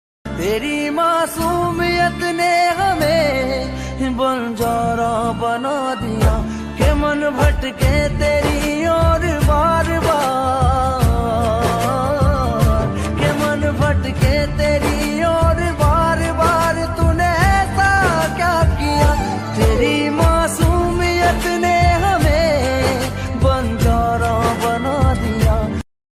sad ringtone only music